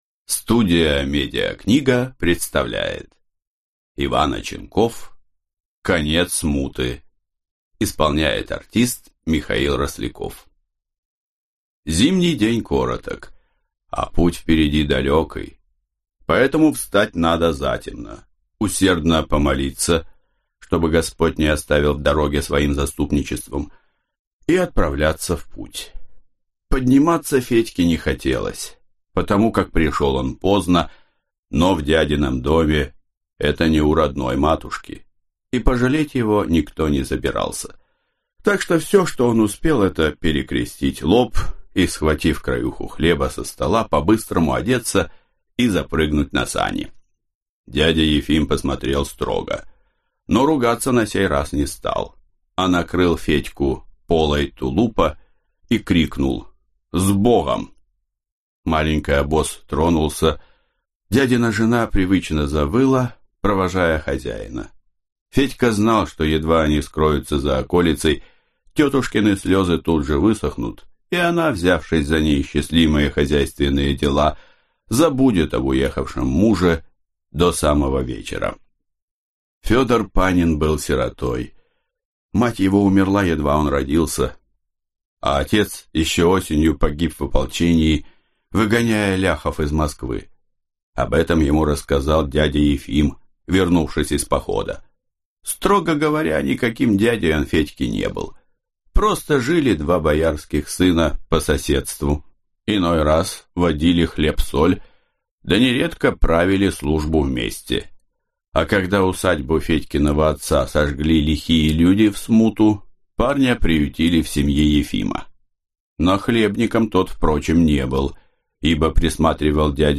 Аудиокнига Конец Смуты | Библиотека аудиокниг
Прослушать и бесплатно скачать фрагмент аудиокниги